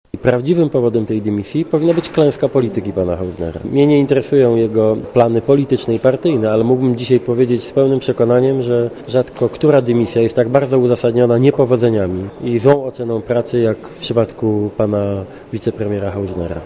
Dymisja jest uzasadniona, ale spóźniona - komentuje odejście z rządu wicepremiera Hausnera lider Platformy Obywatelskiej, Donald Tusk.
Komentarz audio